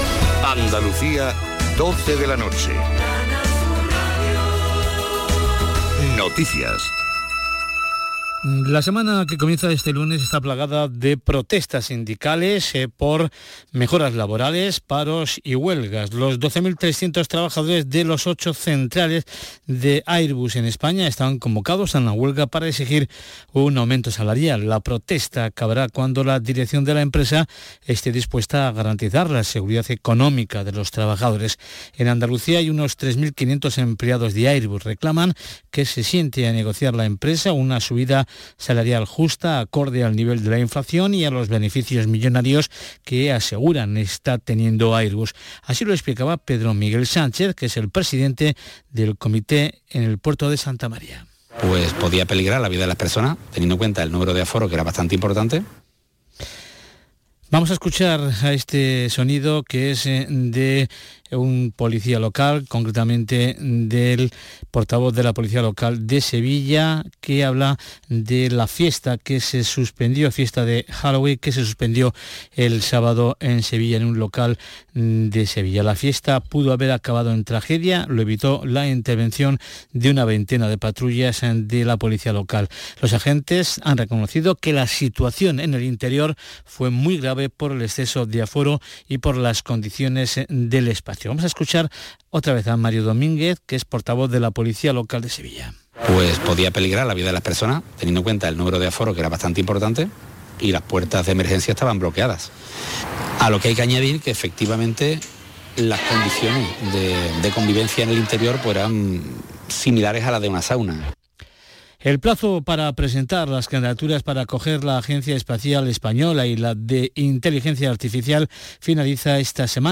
La sal de la tierra. Conversaciones desde Andalucía con Sara Baras